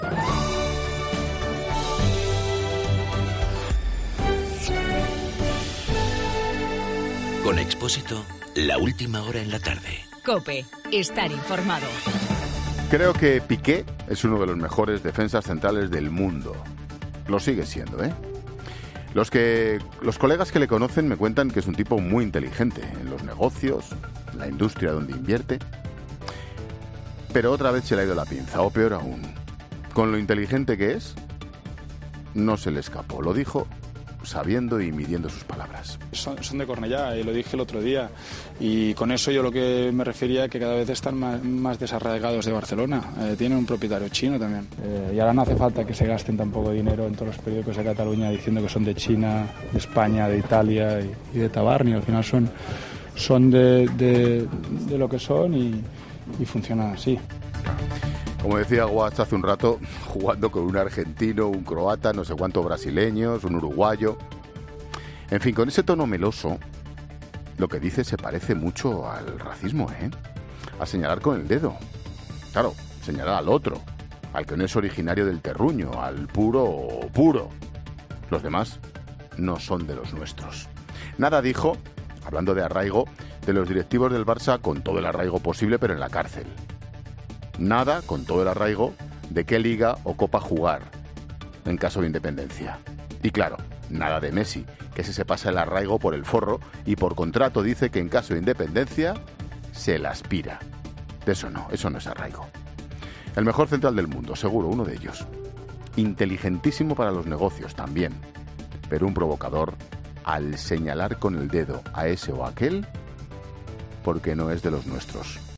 AUDIO: El comentario de Ángel Expósito sobre las palabras del defensa del Barça.